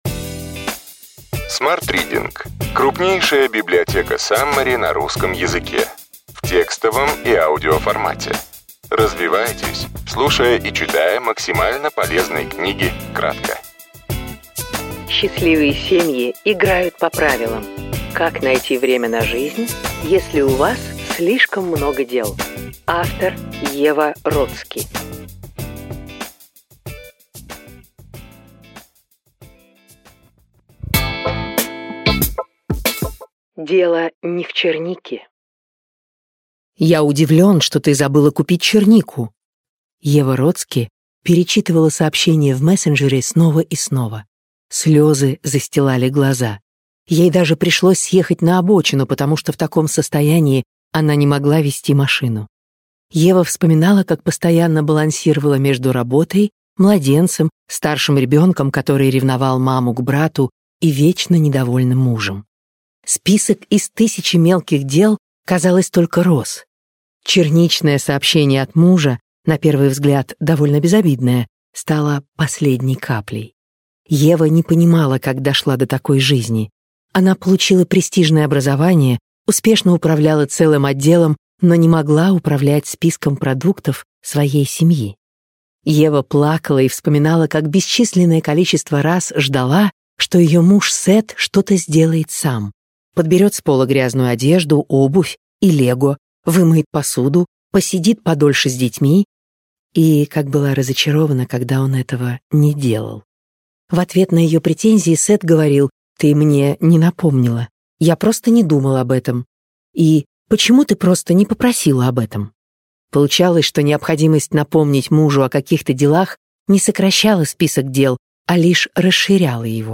Аудиокнига Счастливые семьи играют по правилам. Как найти время на жизнь, если у вас слишком много дел. Ева Родски. Саммари | Библиотека аудиокниг